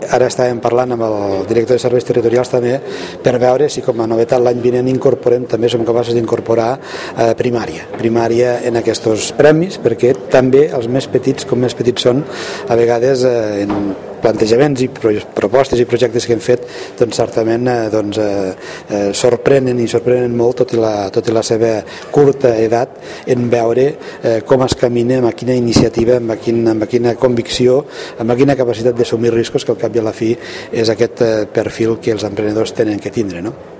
El president de la Diputació de Lleida, Joan Reñé, ha participat en l’acte de lliurament dels premis als millors projectes d’emprenedoria als ensenyaments secundaris de Lleida, on ha anunciat que estan valorant la possibilitat d’incorporar els alumnes de Primària a la propera convocatòria.